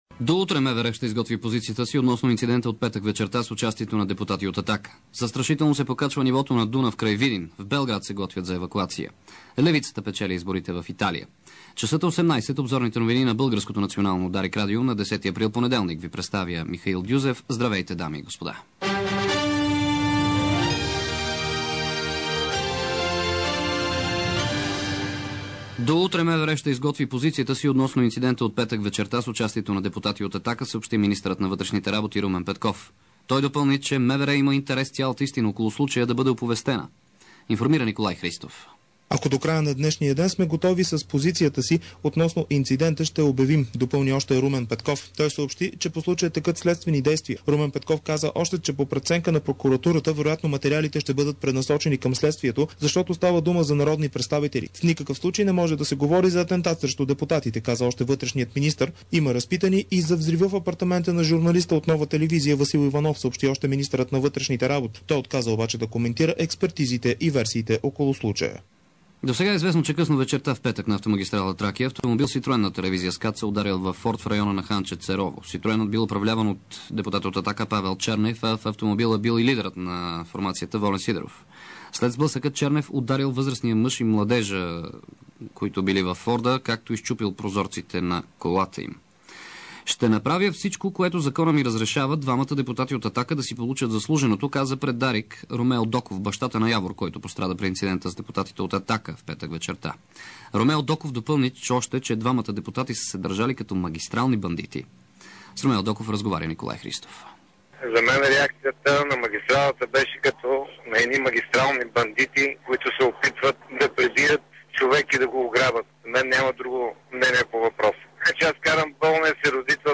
DarikNews audio: Обзорна информационна емисия – 10.04.2006 г.